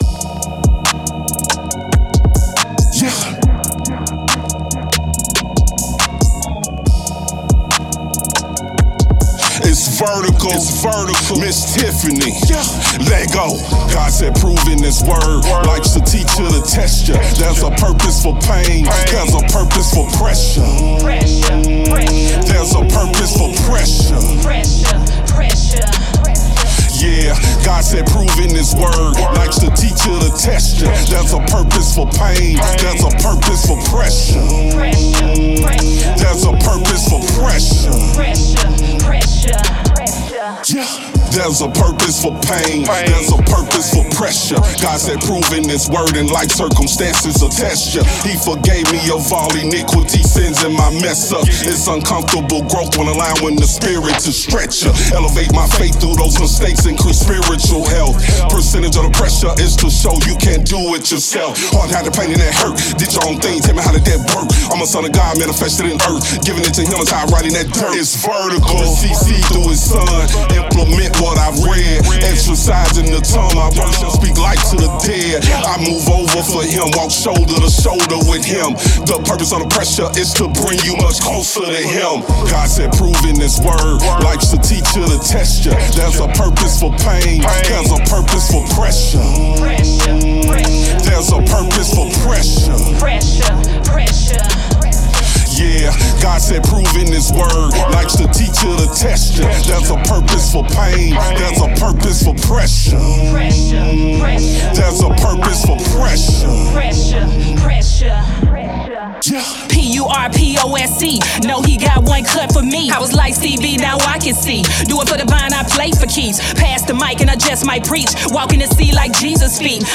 Genre: Christian / Gospel / Hip Hop / Rap